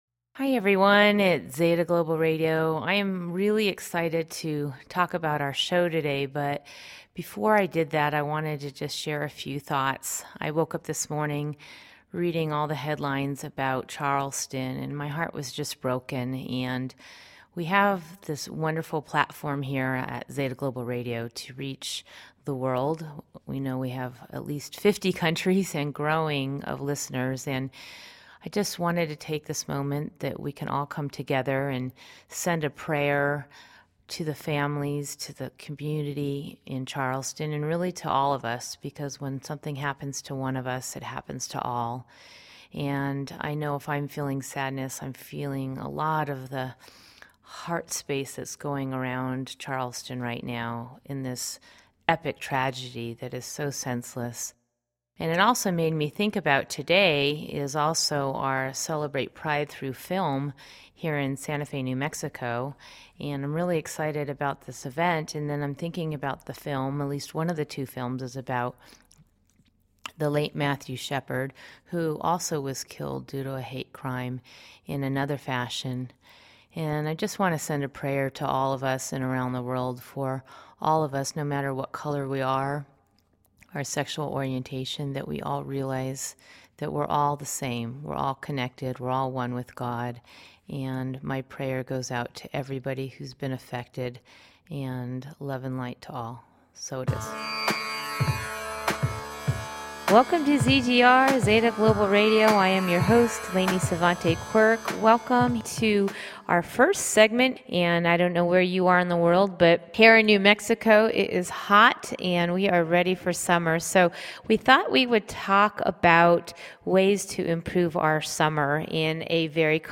Talk Show Episode
Join us and four incredible women who are imparting some incredible ways to clear the old, enter the new and leave our body feeling refreshed and energized.